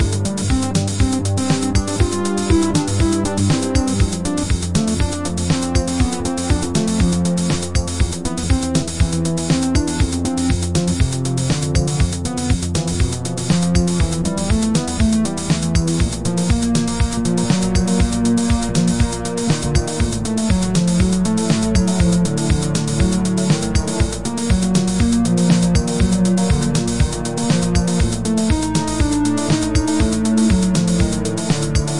标签： 快乐 循环 旋律 音乐 gameloop 合成器 游戏 游戏 器官 声音
声道立体声